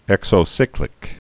(ĕksō-sĭklĭk, -sīklĭk)